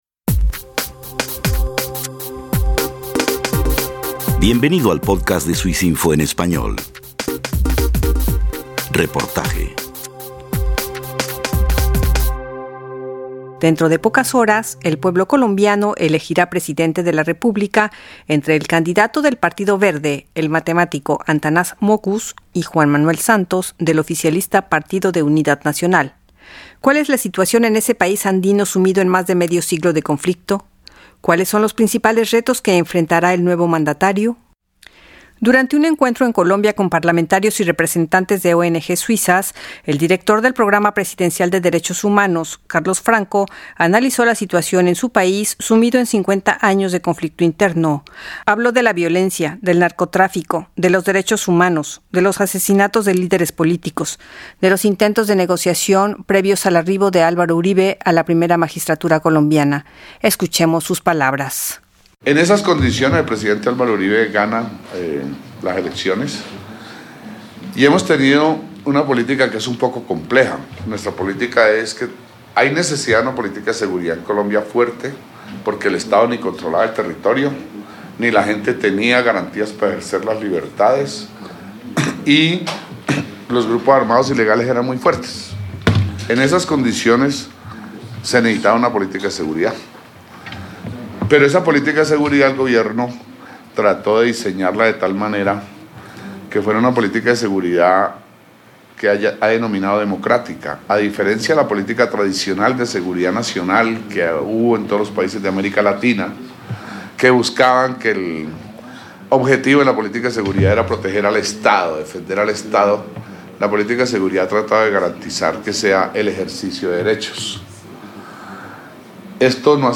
Análisis del Dr. Carlos Franco, asesor presidencial en DD HH